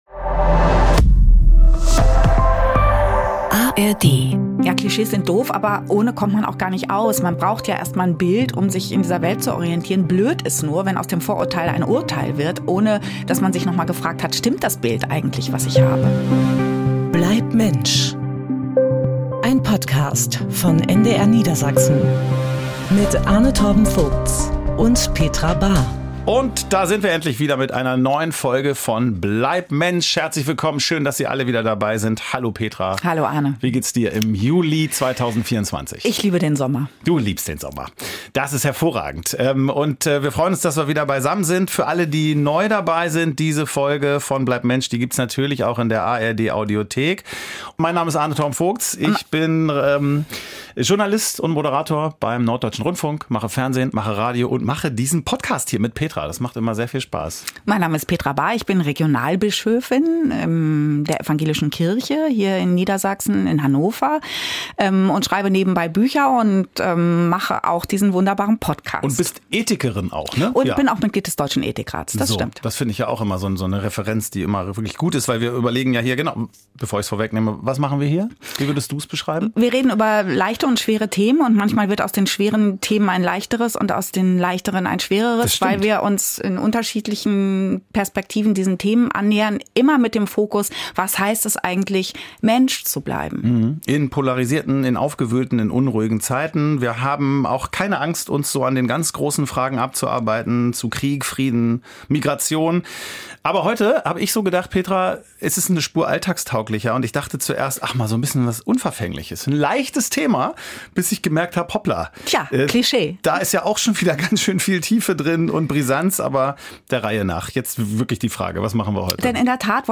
Gespräche mit Tiefgang